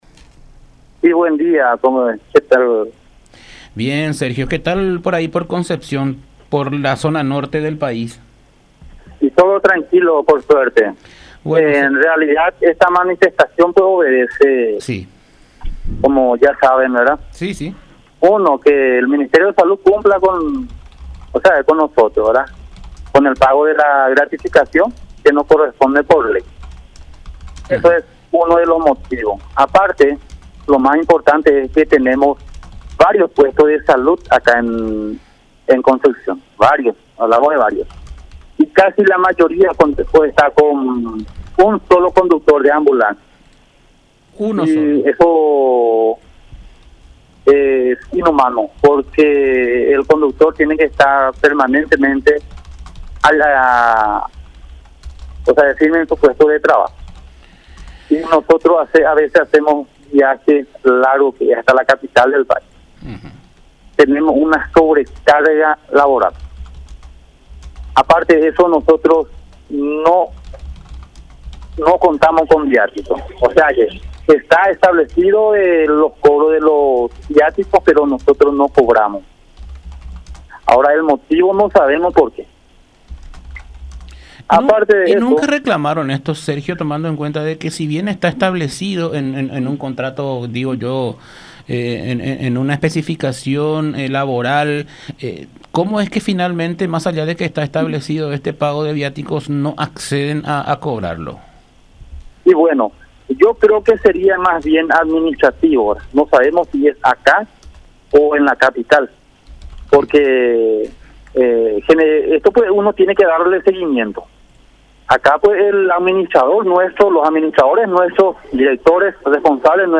en diálogo con La Unión R800 AM